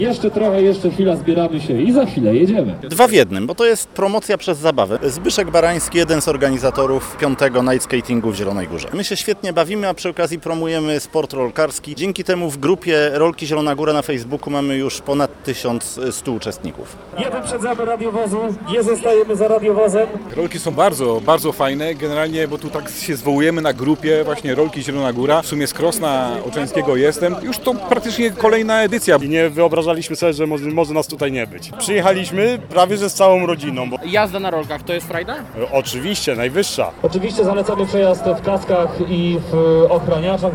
Przed rozpoczęciem odbyły się także warsztaty bezpiecznego hamowania: